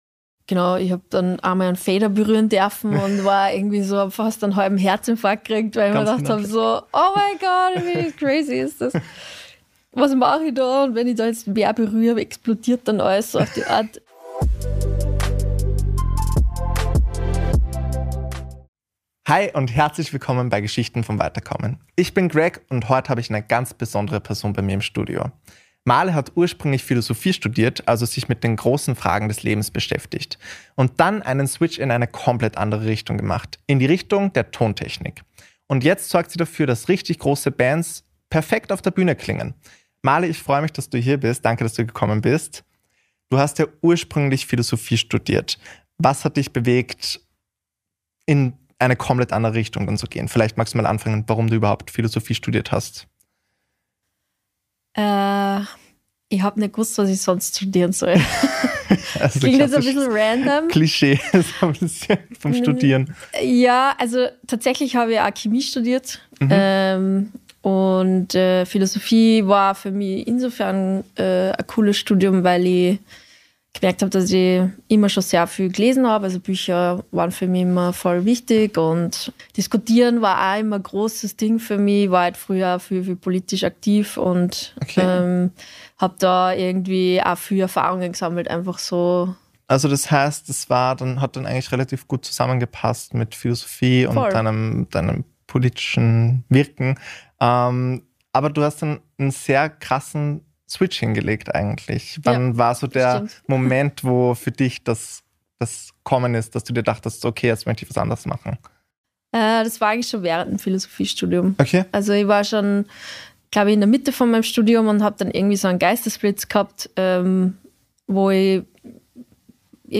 Von Theorie zu Ton ~ Geschichten vom Weiterkommen – Wiener Gespräche über berufliche Veränderung Podcast